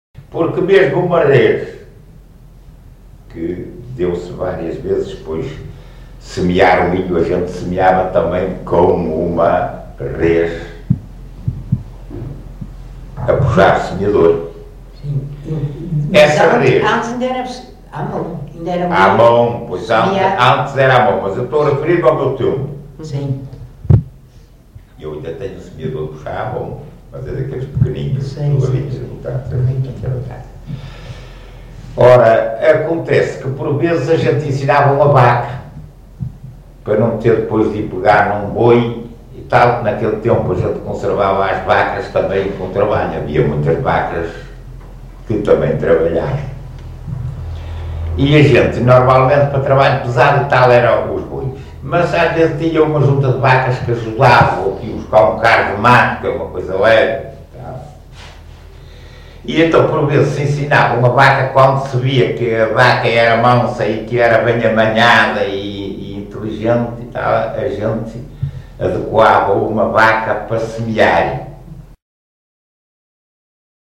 LocalidadeGião (Vila do Conde, Porto)